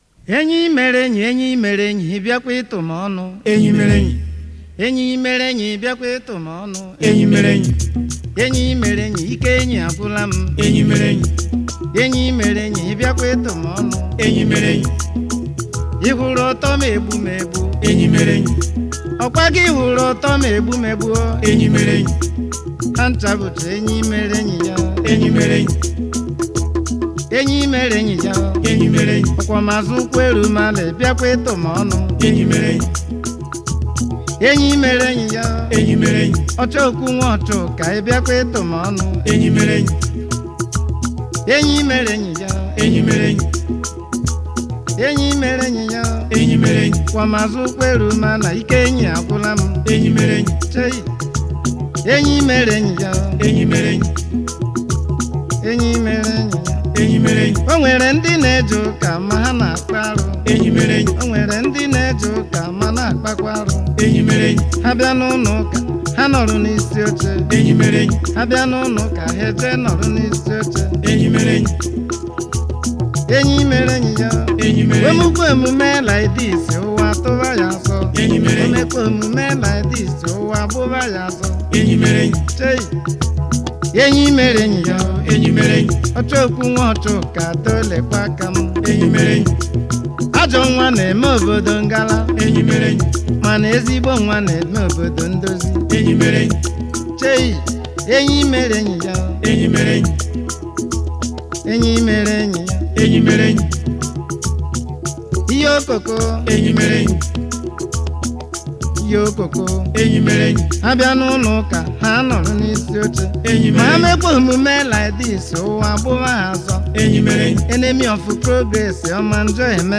a well versed highlife track
a good highlife tune